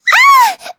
Taily-Vox_Damage_03.wav